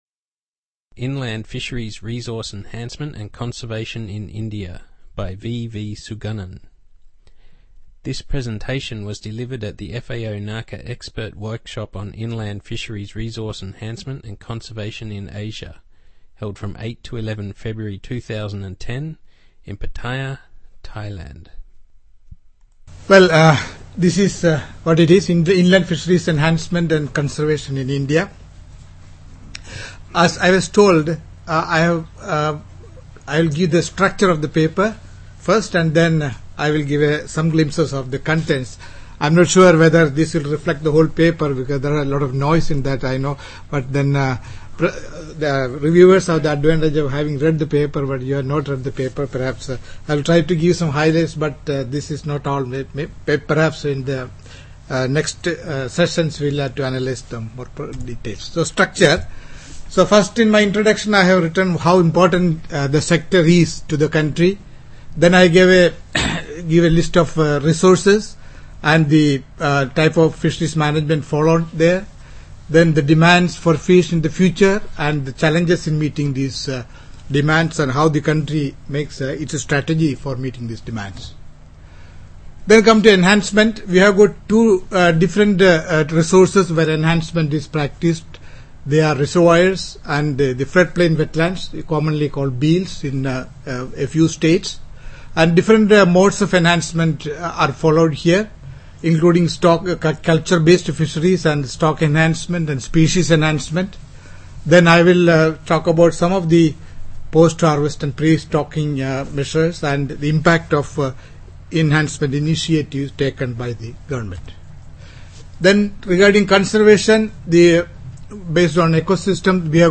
Presentation on inland fisheries resource enhancement and conservation in India